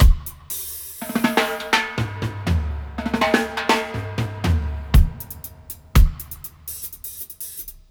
121-FX-05.wav